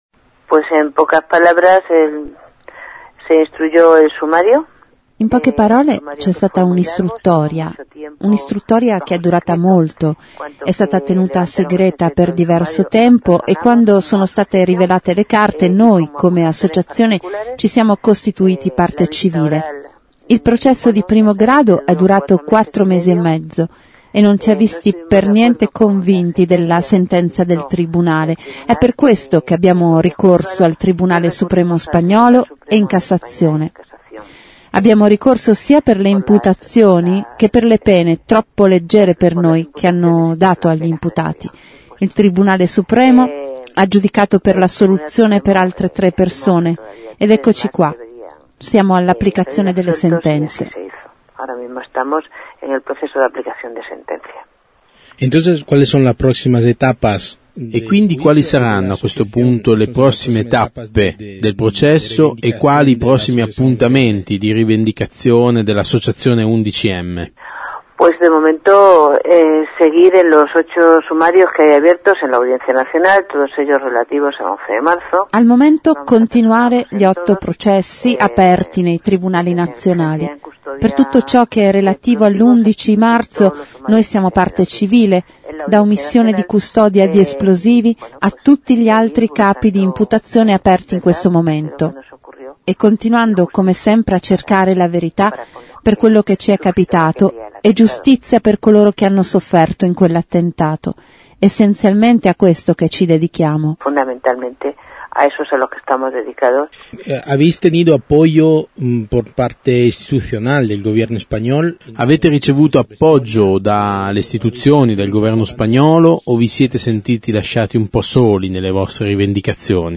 intervista11m_persito
intervista11m_persito.mp3